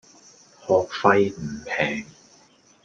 Голоса - Гонконгский 430